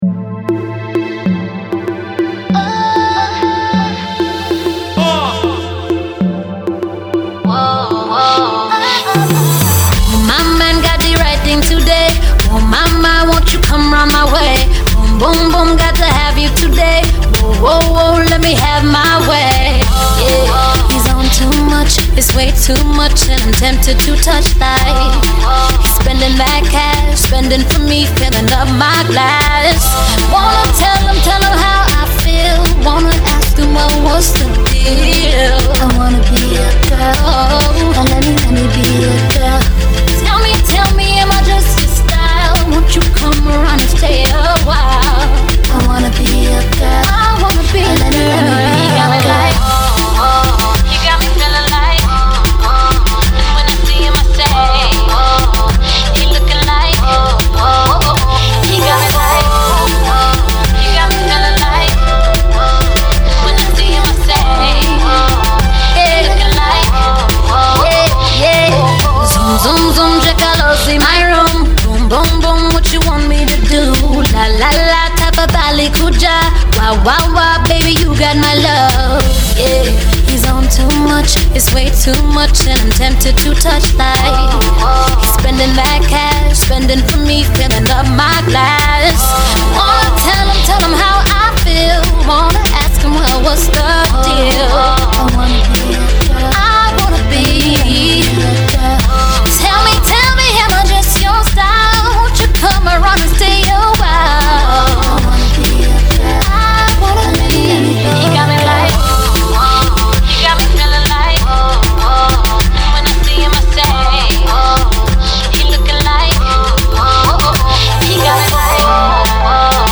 I can see the ladies slow winding to this.